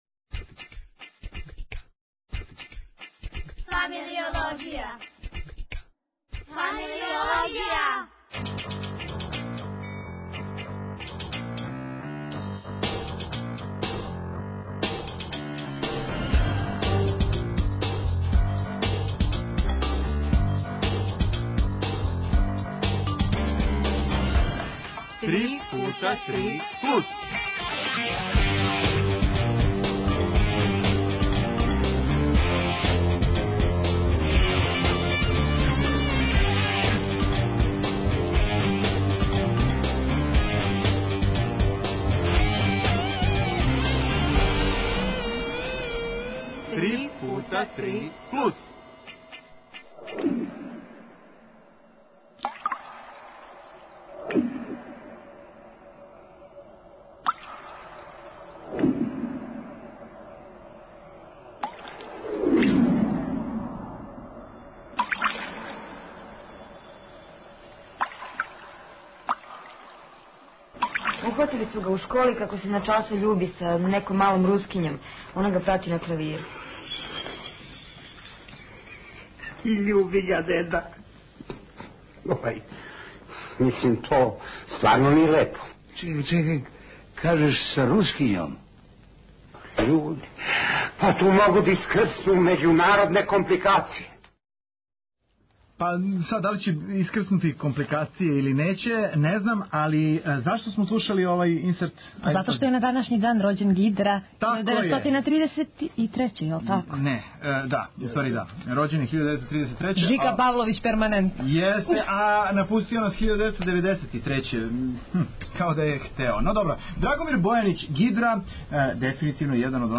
О сликарству, вајарству, архитектури, музици, поезији и Уметности уопште, разговарала смо